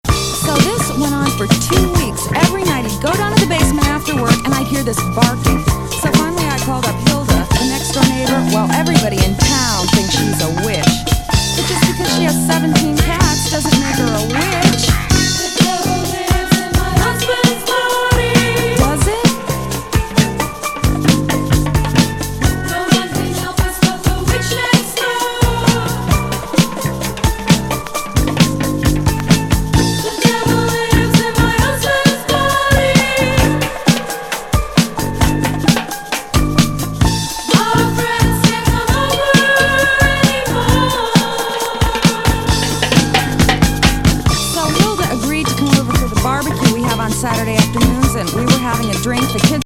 スティールパン入りトロピカル・
ガールズNWディスコ12″!